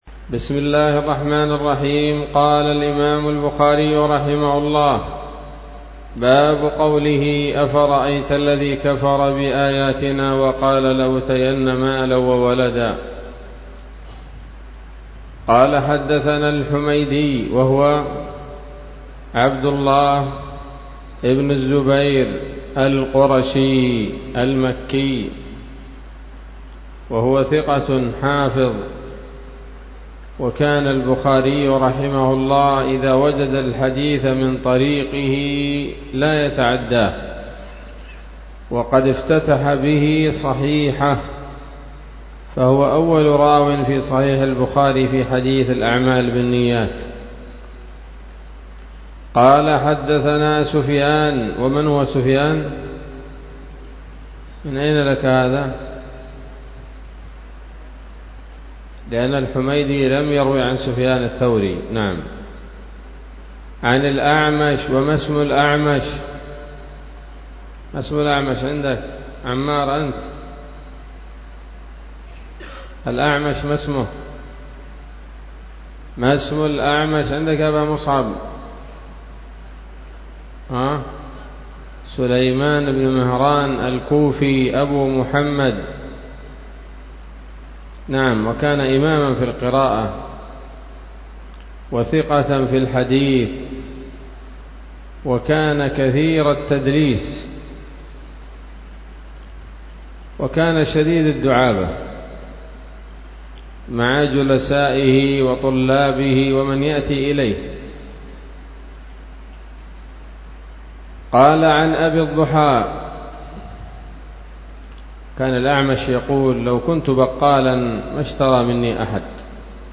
الدرس الثامن والستون بعد المائة من كتاب التفسير من صحيح الإمام البخاري